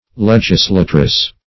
Search Result for " legislatress" : The Collaborative International Dictionary of English v.0.48: Legislatress \Leg"is*la`tress\ (-tr[e^]s), Legislatrix \Leg"is*la`trix\ (-tr[i^]ks), n. A woman who makes laws.